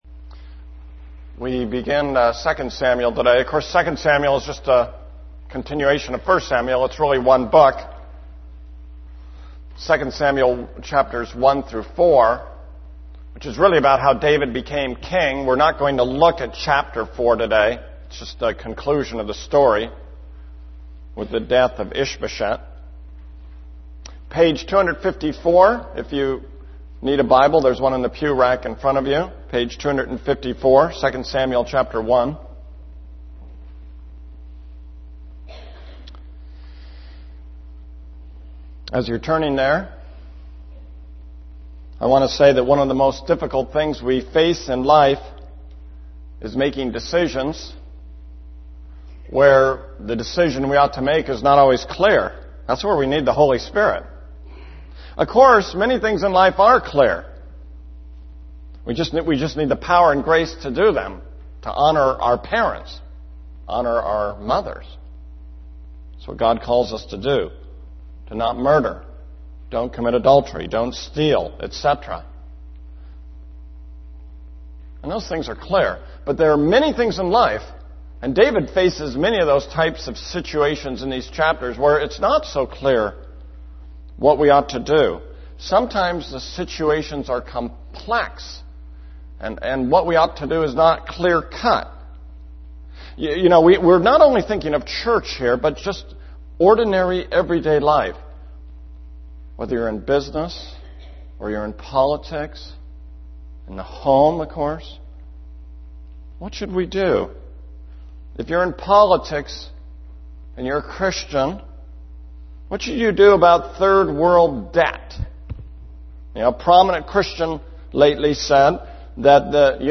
This is a sermon on 2 Samuel 1:1-4:12.